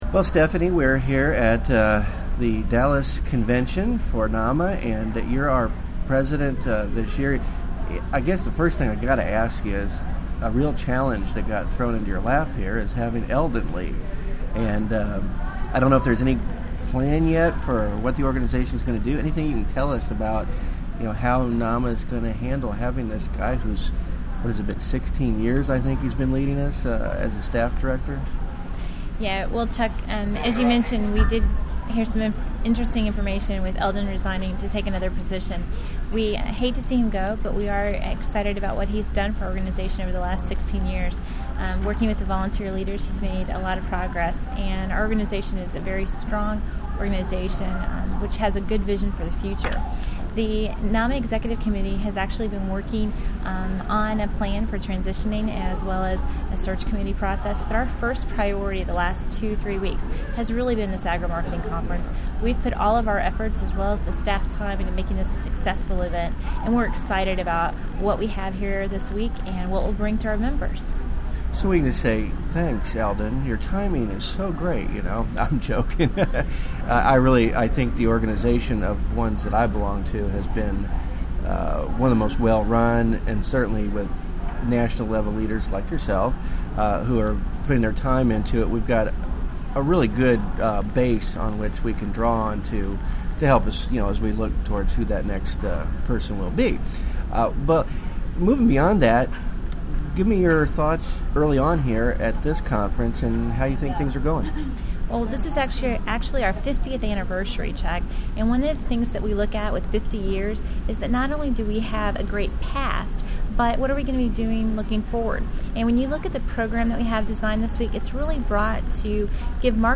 I recorded a short conversation with her this morning.